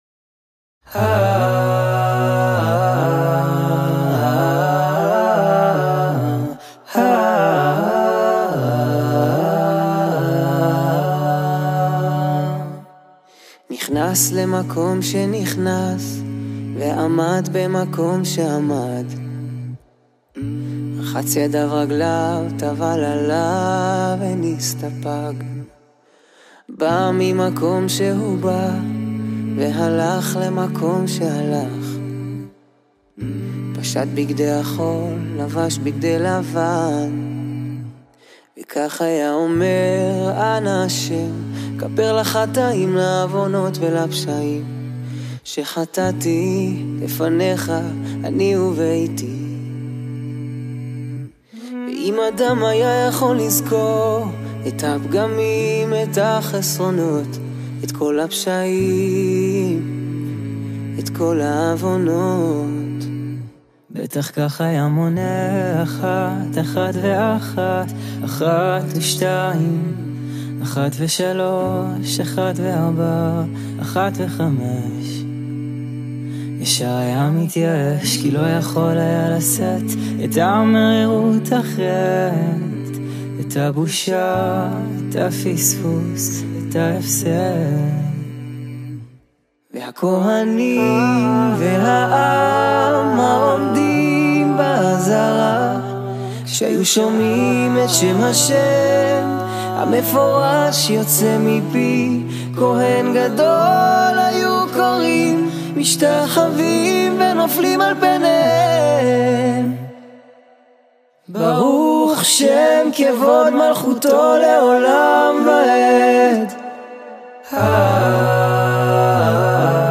ווקאלי